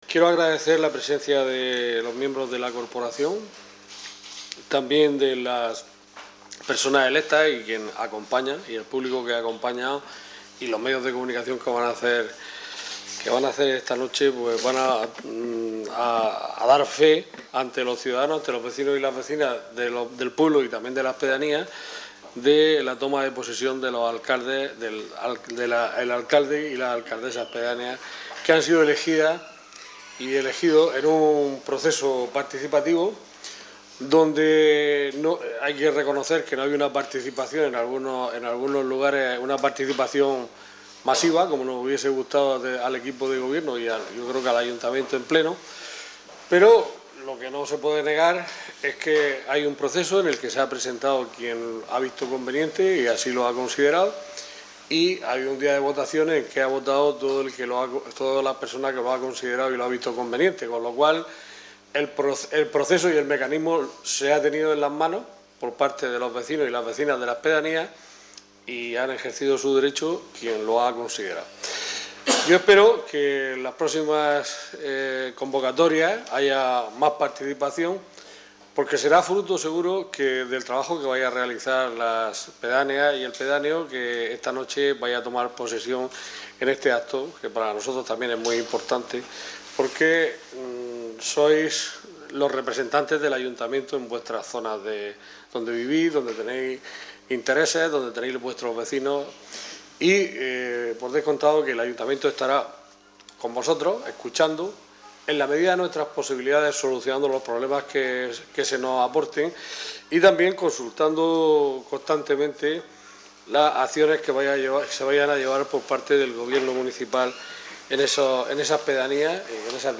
El alcalde de Totana, Juan José Cánovas, presidió anoche el acto de toma de posesión de los nuevos alcaldes-pedáneos que representarán a los vecinos de las diputaciones durante la legislatura 2015/2019, cuyo acto institucional se celebró en el salón de plenos municipal.